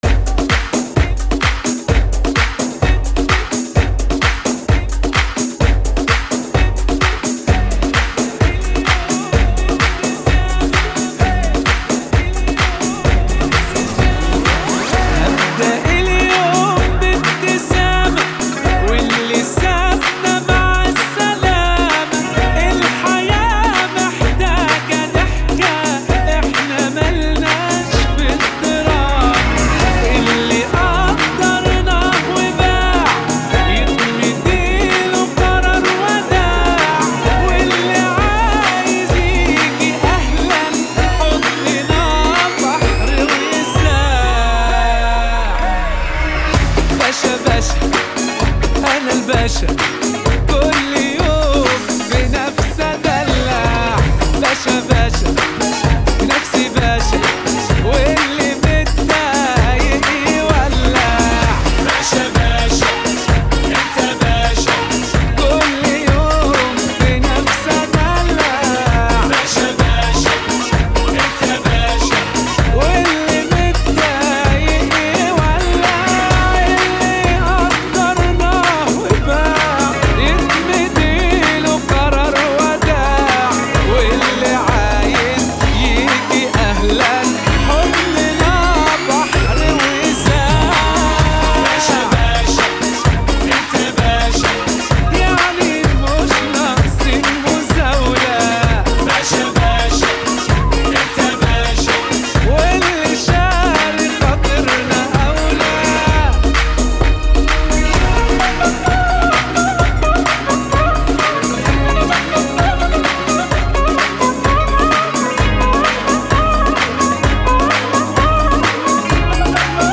[ 129 bpm ] 2022